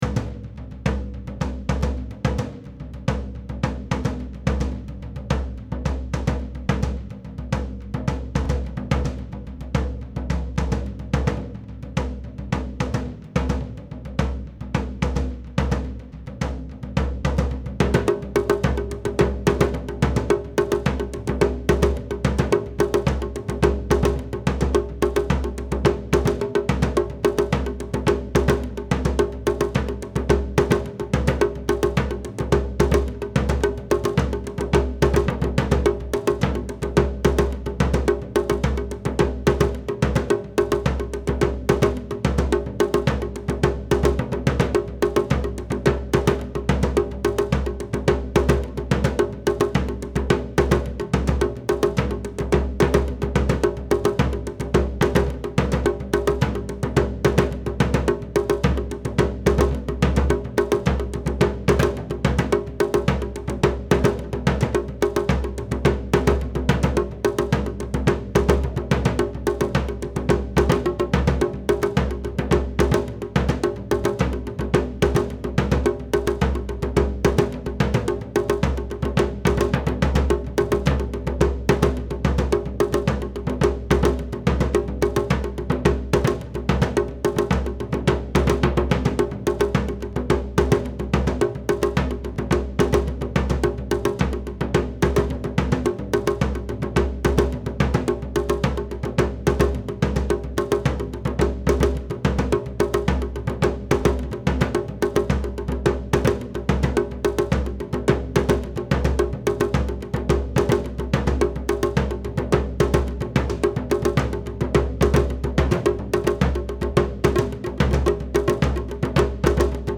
Ready Set kaDON Shime and Okedo PARTS
Ready-Set-kaDON-Shime-and-Okedo-PARTS.mp3